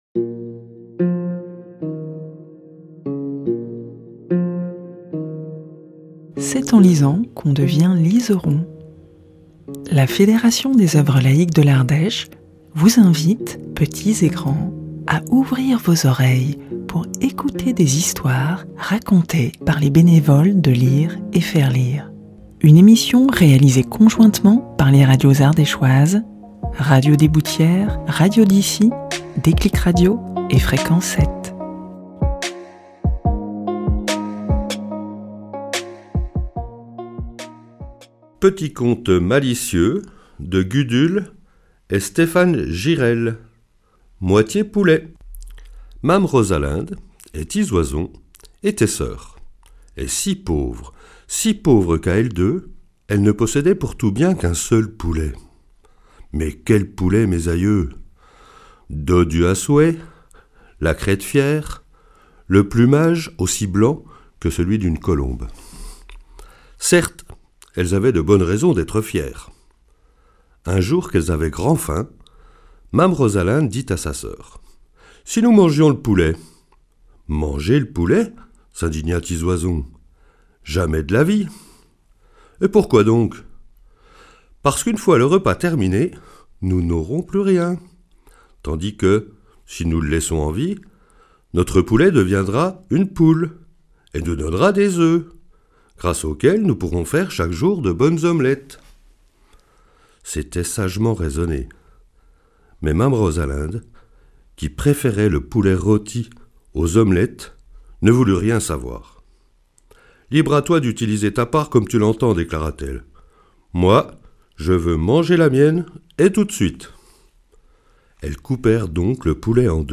Une chronique initiée par la FOL (Fédération des œuvres laïques) de l'Ardèche, contée par les lecteurs de l'association Lire et Faire Lire et réalisée conjointement par les radios associatives d'Ardèche (RDB, Déclic radio, Radio d'ici et Fréquence 7). Au programme : contes, poésie, histoires courtes...